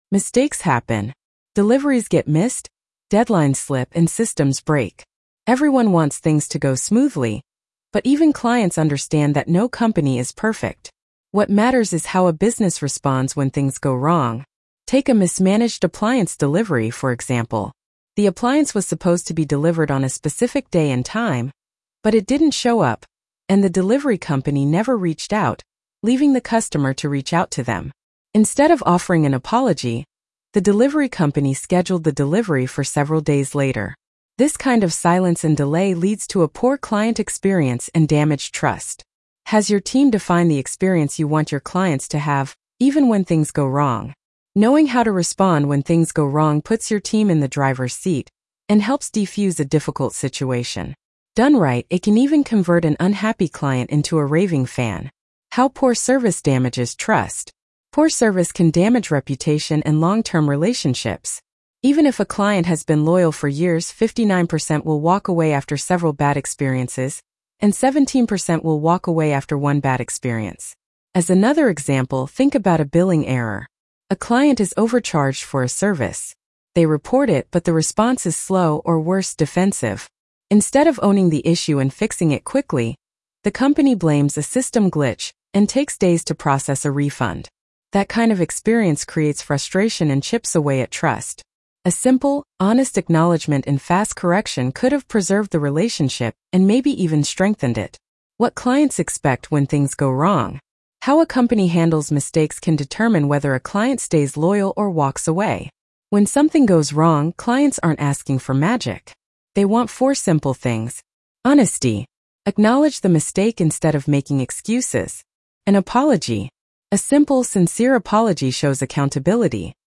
Messed Up Heres How to Make it Right with Clients Blog Narration.mp3